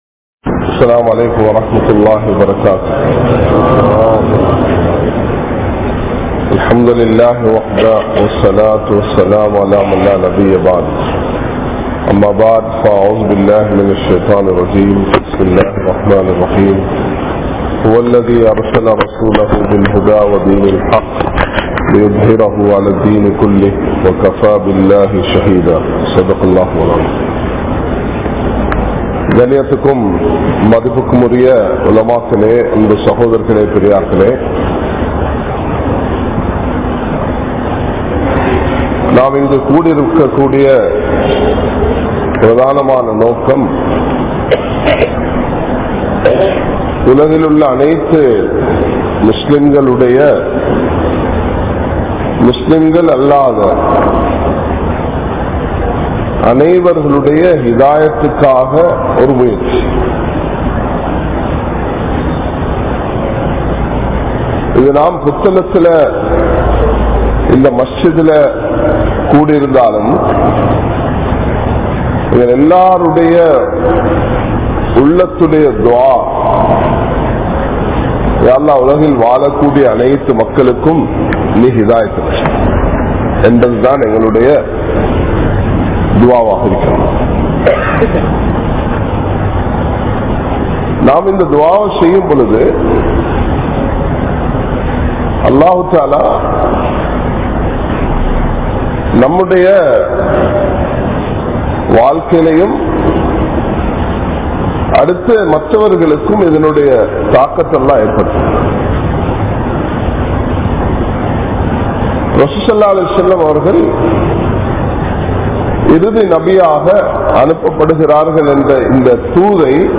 Thiyaahaththudan Vaalungal (தியாகத்துடன் வாழுங்கள்) | Audio Bayans | All Ceylon Muslim Youth Community | Addalaichenai